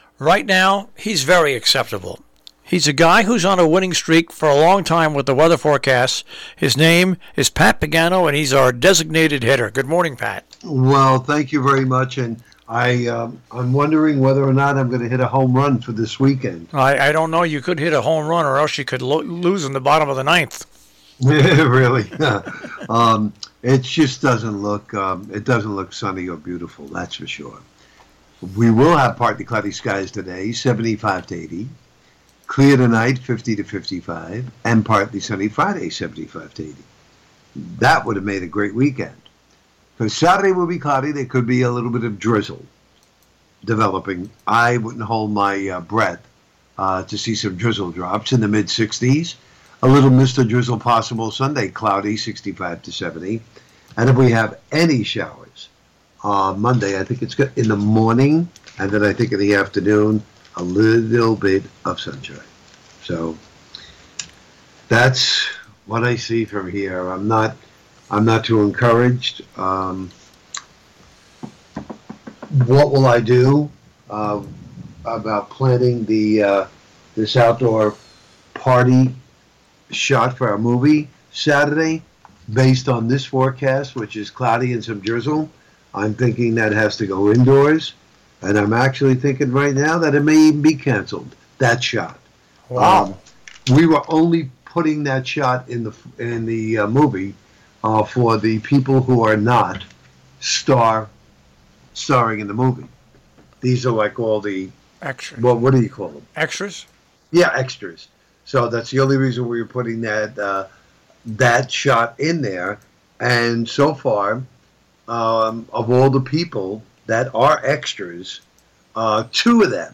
Your Robin Hood Radio Tri-State Forecast